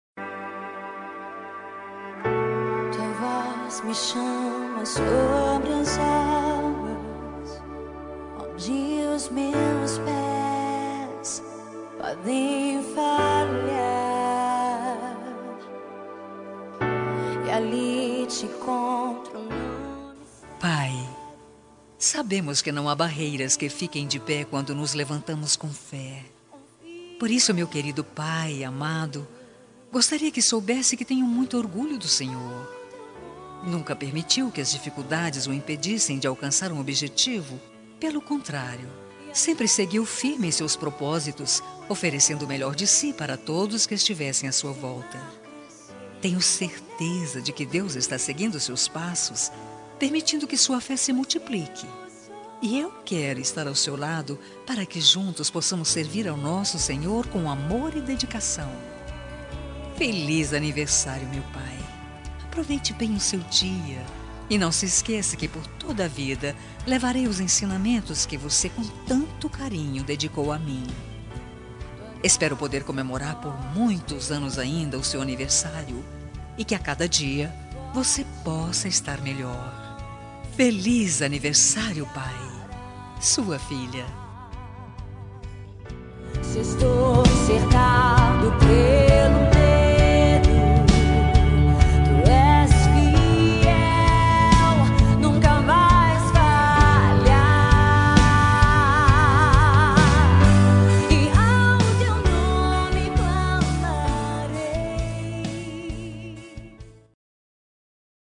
Aniversário de Pai Gospel – Voz Feminina – Cód: 6080